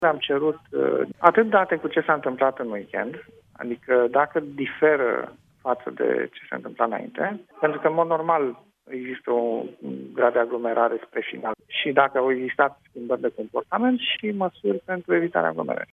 Prefectul Capitalei, Alin Stoica spune că le-a cerut reprezentanților acestor magazine să vină cu propuneri și soluții pentru a reduce aglomerația în zilele de weekend, dar și măsuri pentru a limita numărul de oameni din interiorul unui magazin:
05apr-13-prefect-discutam-cu-magazinele-.mp3